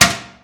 Toilet Seat Drop Sound
household
Toilet Seat Drop